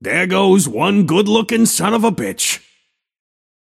Shopkeeper voice line - There goes one good-lookin‘ son of a bitch.